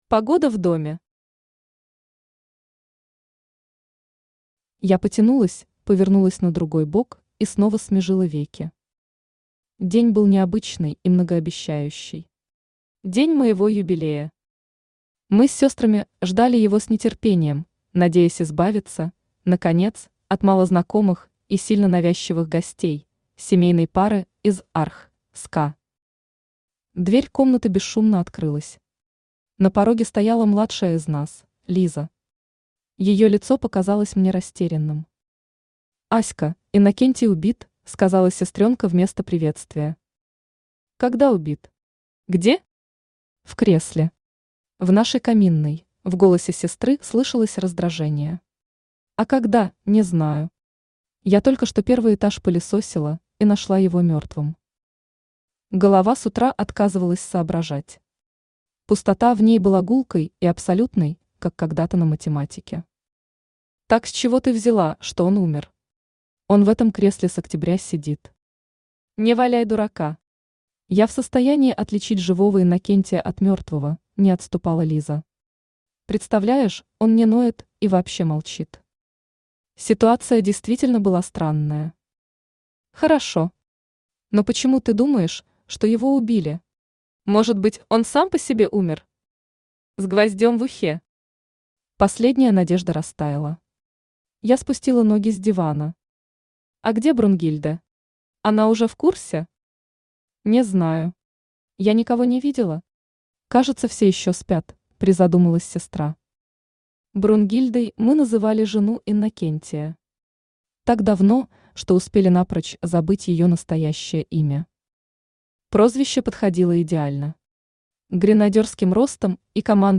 Аудиокнига Веселые картинки, или Сами себе тётушки | Библиотека аудиокниг
Aудиокнига Веселые картинки, или Сами себе тётушки Автор Екатерина Владимировна Зинькова Читает аудиокнигу Авточтец ЛитРес.